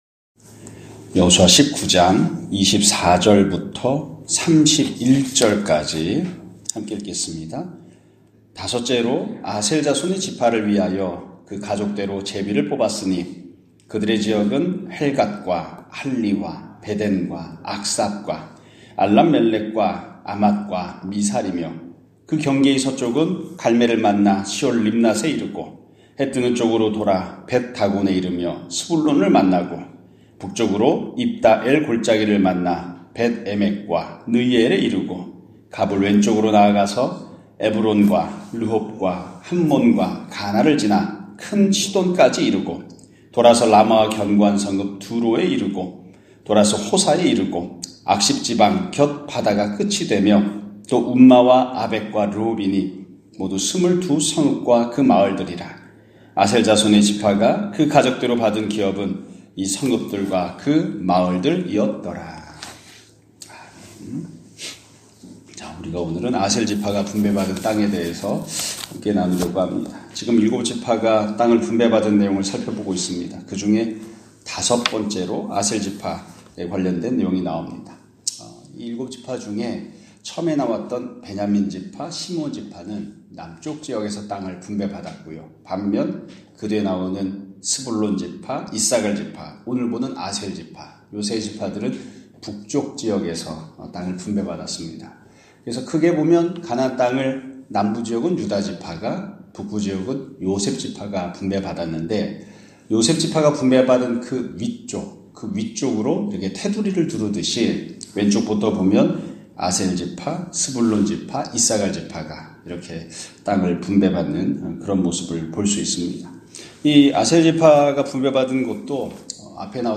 2025년 1월 8일(수 요일) <아침예배> 설교입니다.